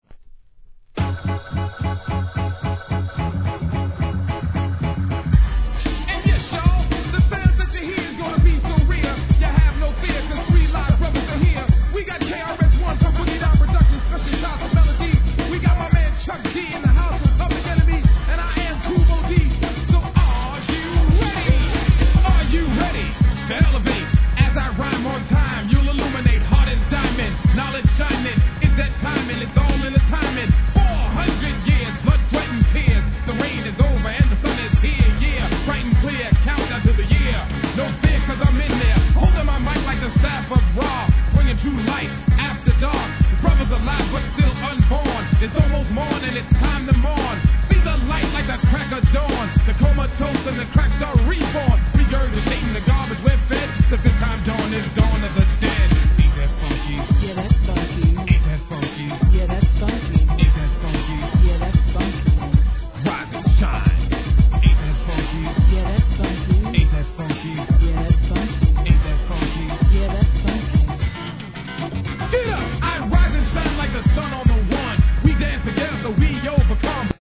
HIP HOP/R&B
疾走感溢れるMICリレー!!